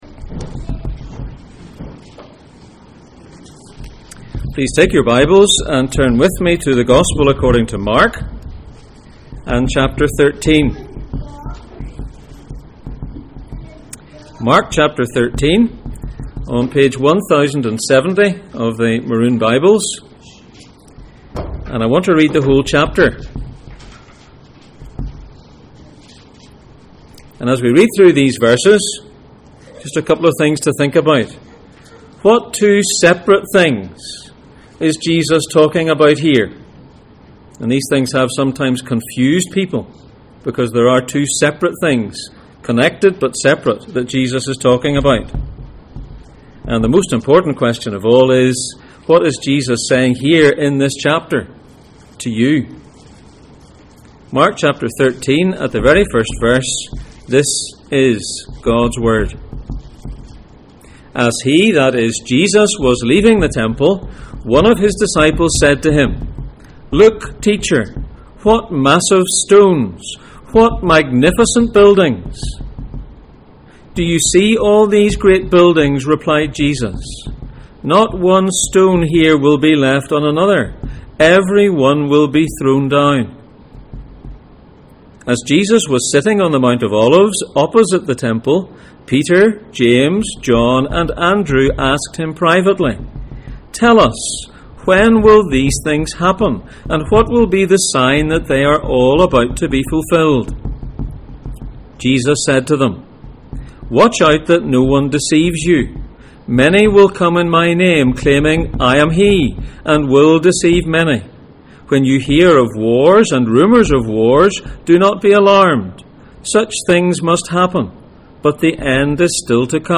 Isaiah 61:1-2 Service Type: Sunday Morning %todo_render% « She put in all that she had What do you say?